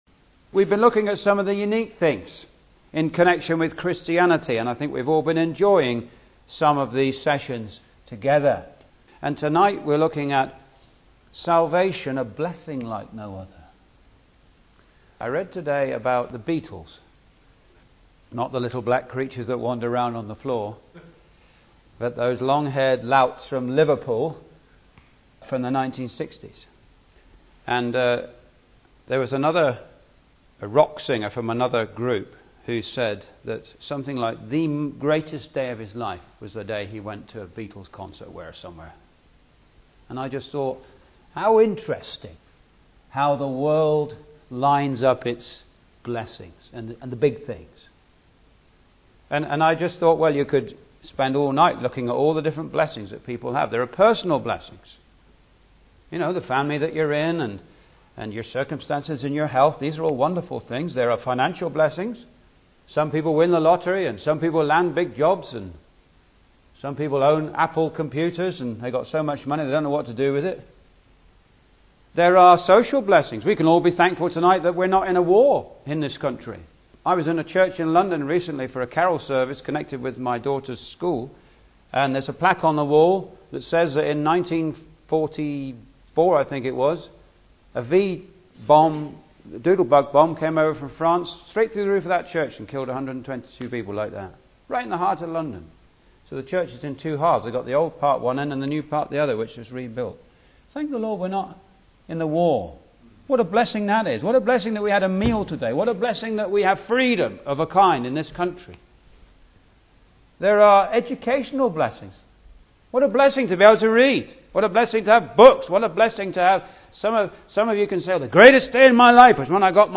It is unique in a 7-fold way: in its means, scope, value, character, effect, terms and outcome. Nothing in this world comes close to the altogether wonderful blessing of salvation through Jesus Christ. (Message preached 29th Jan 2015)